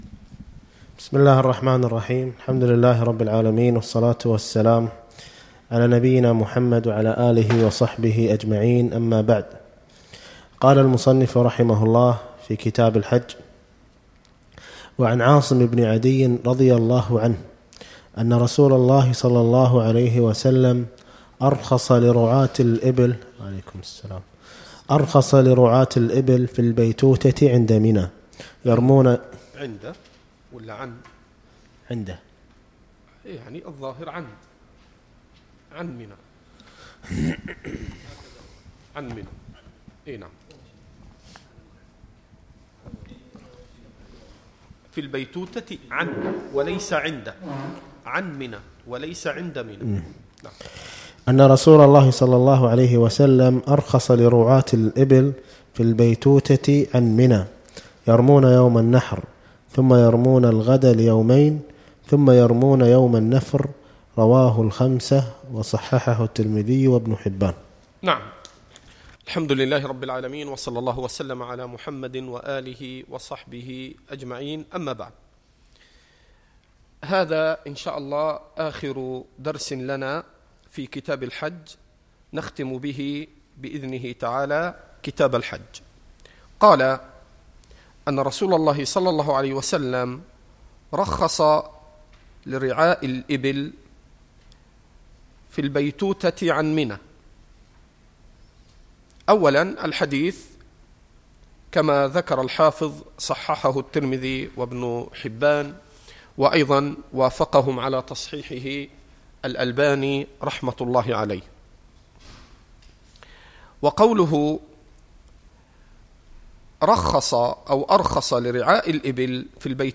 الدرس السابع عشر والاخير شرح كتاب الحج من بلوغ المرام